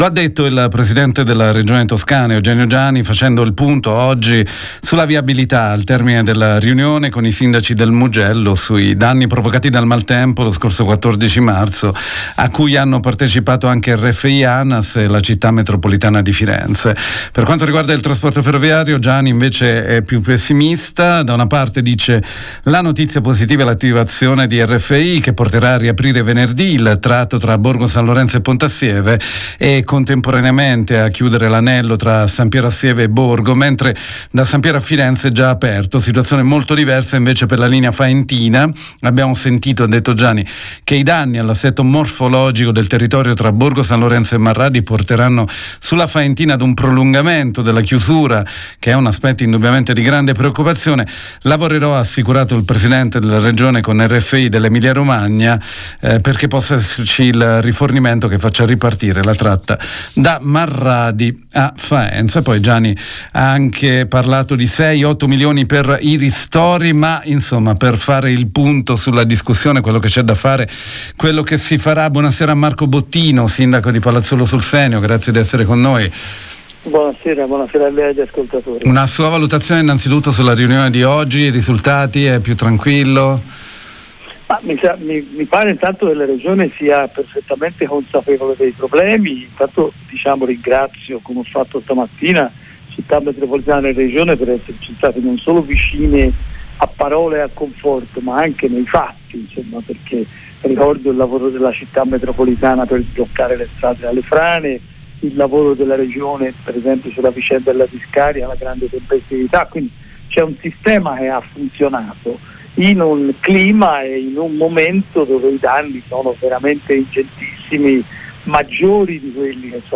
Rovigo, Palazzuolo sul SenioDopo la riunione in Regione Toscana che ha fatto il punto degli interventi dopo l’ondata di maltempo di due settimane fa, abbiamo intervistato il sindaco di Palazzuolo sul Senio, Marco Bottino